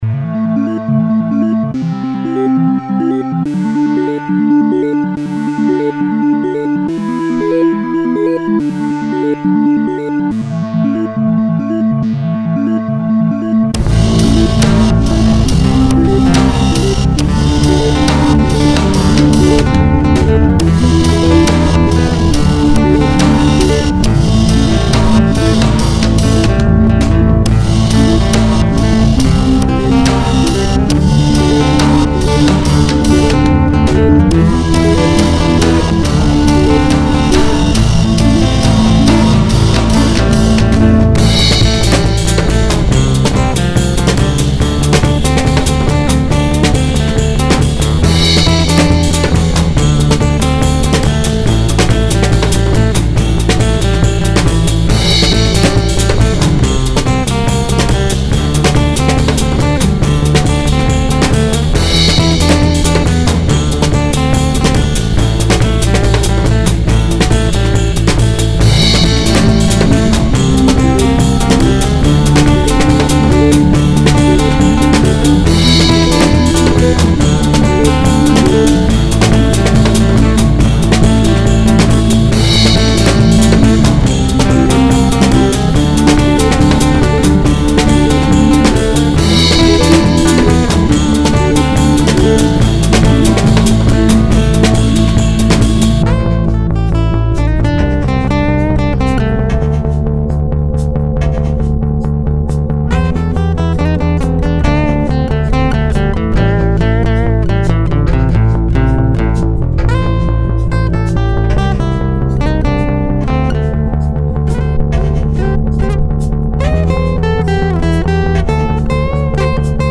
dance/electronic
House
Techno
Breaks & beats